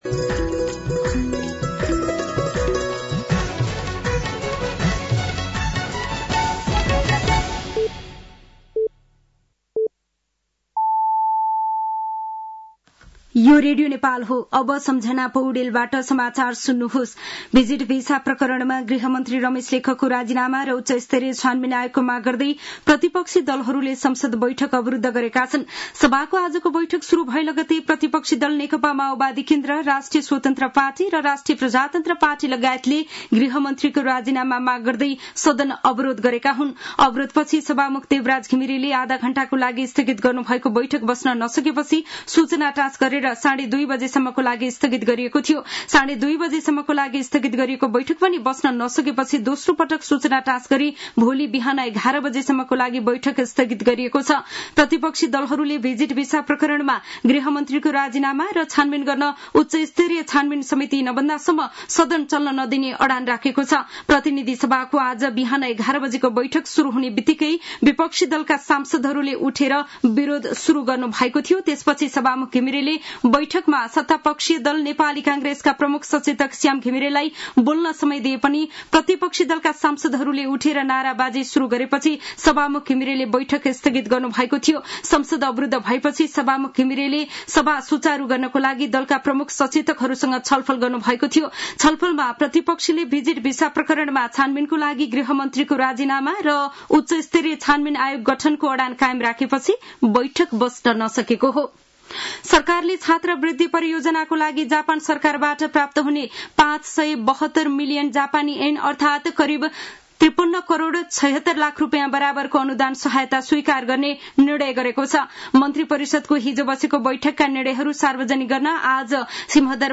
An online outlet of Nepal's national radio broadcaster
साँझ ५ बजेको नेपाली समाचार : १३ जेठ , २०८२